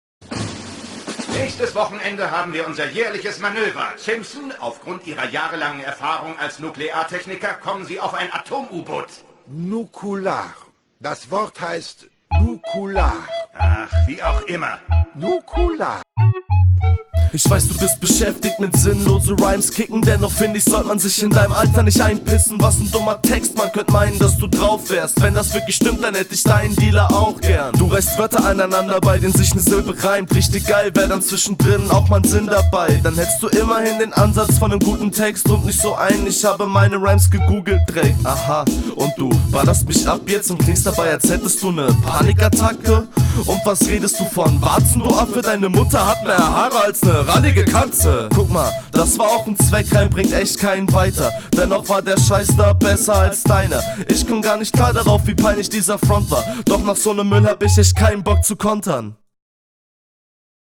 Kommst viel smoother und angenehmer, …